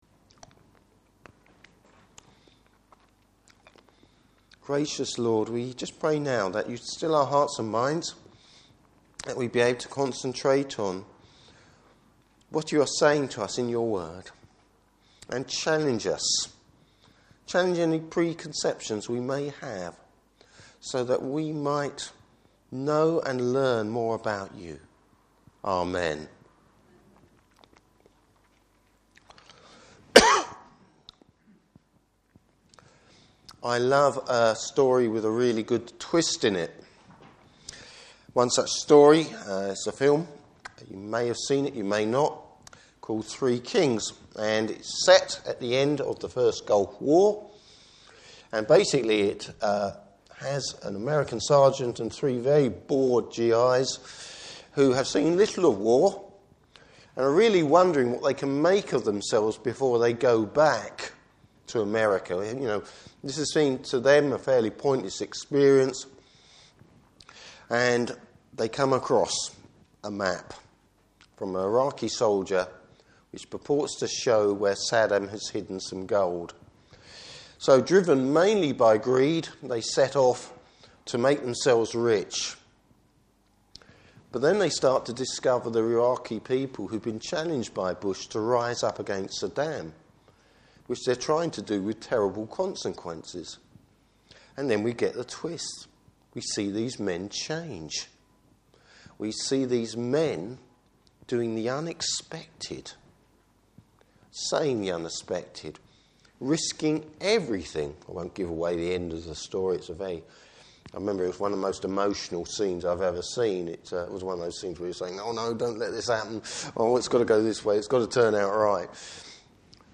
Service Type: Morning Service Bible Text: Luke 5:27-39.